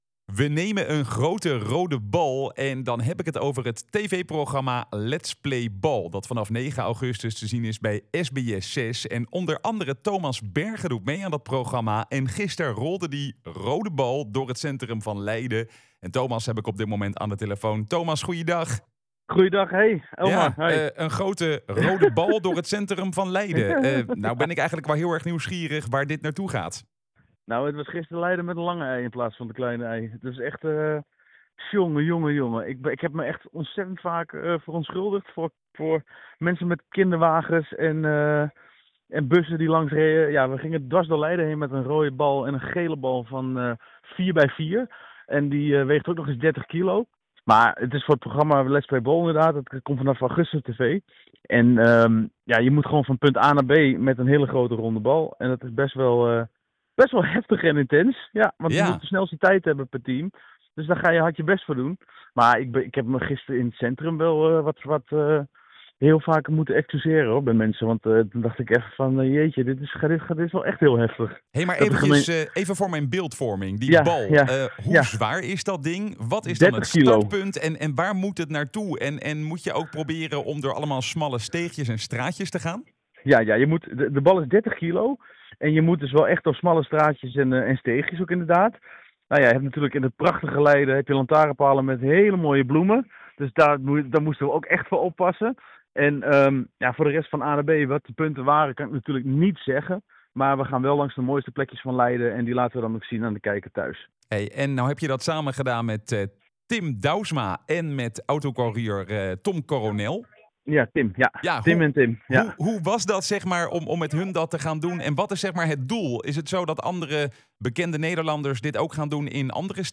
in gesprek met Thomas Berge over opnames Let’s Play Ball